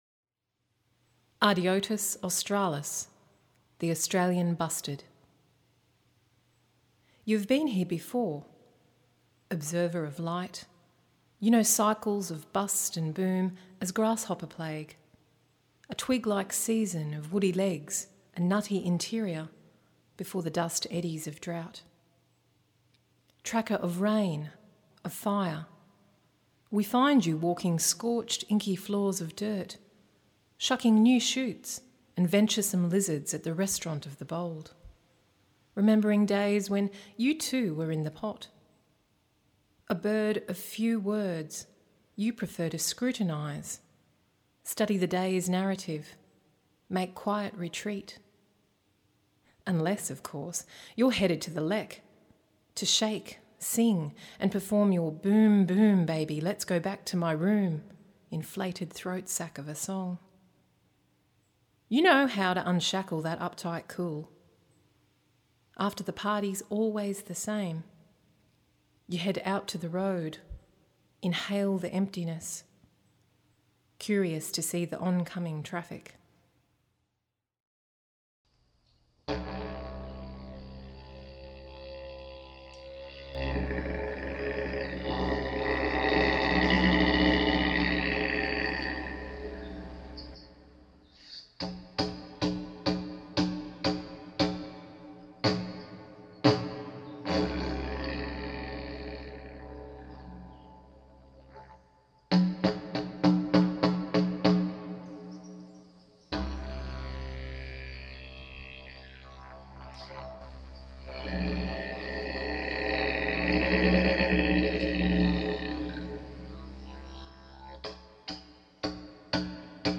At the talk we also looked at the artwork and listened to the audio mix for the Australian Bustard (see below).
Didgerido